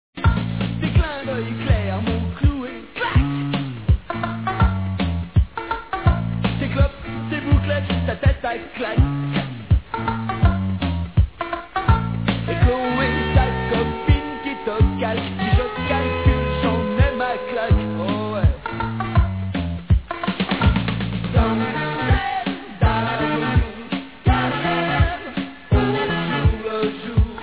"Pop-Rock"